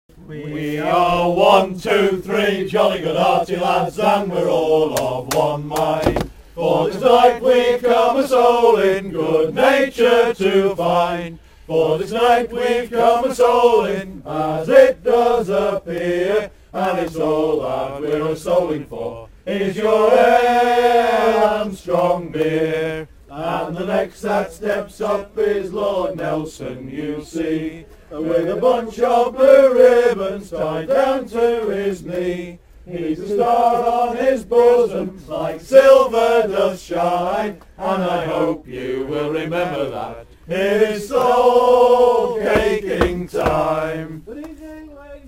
Wednesday 31st October 2007 outside the Drum and Monkey in Comberbach. A guest appearance as "Wild Horse" with the splendid Comberbach Soulcakers.